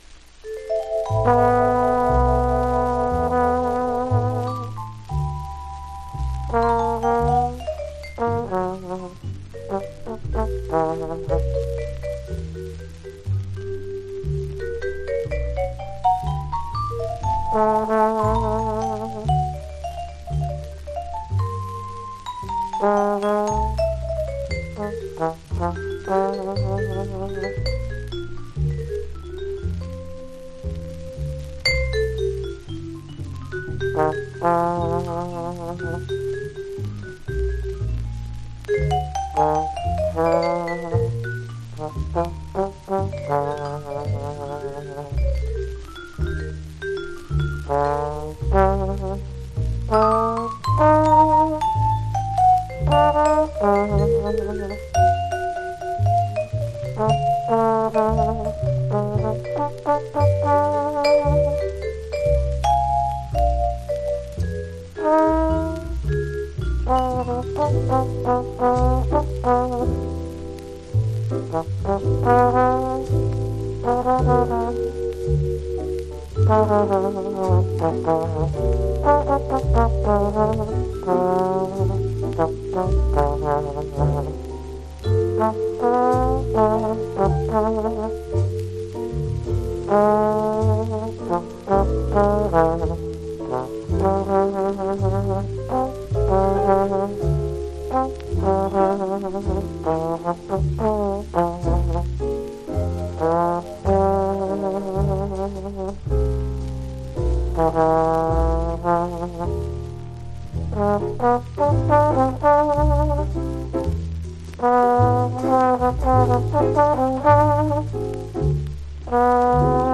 （プレスによりチリ、プチ音、サーノイズある曲あり）
Genre US JAZZ